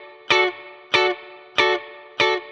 DD_StratChop_95-Dmaj.wav